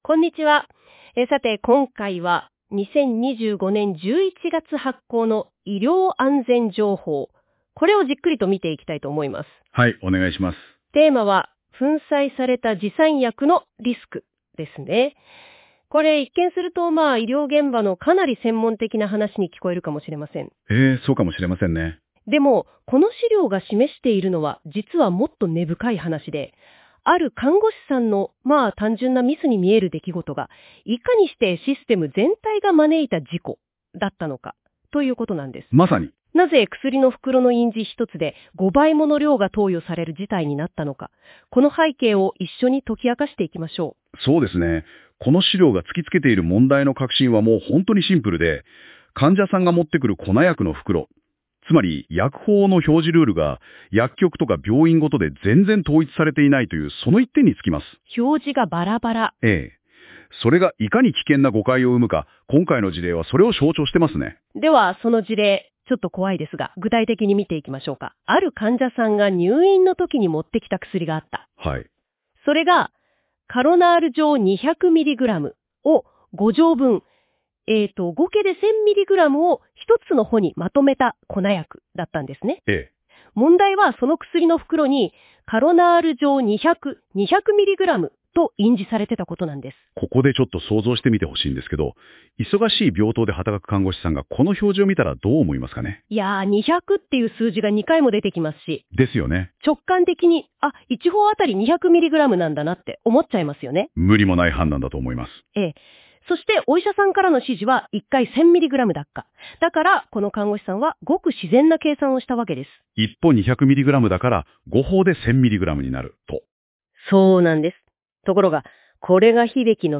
当法人では、医療機能評価機構が発行する「医療安全情報」について、理解を深めていただくための音声解説を配信しております。なお、本ページに掲載している音声は、AIによる自動音声合成で作成しています。そのため、一部に読み方やイントネーションなど不自然に感じられる箇所がありますが、あらかじめご了承ください。